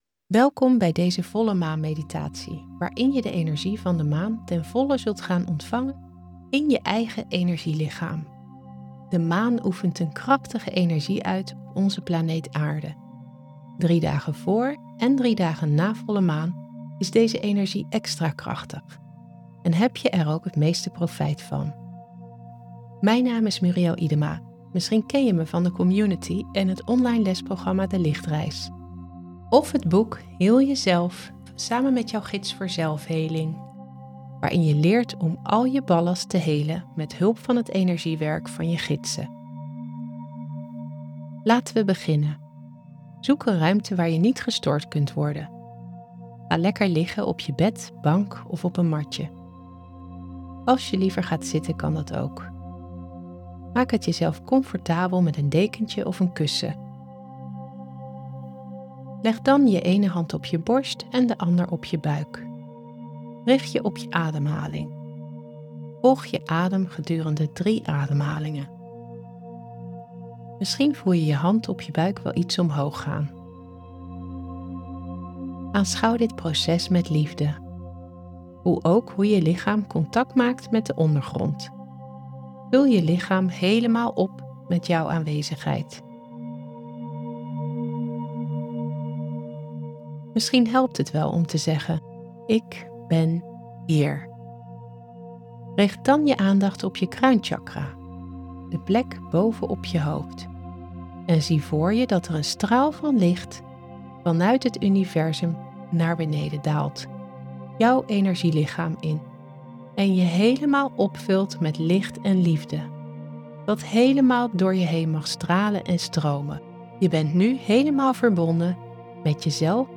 Volle maan meditatie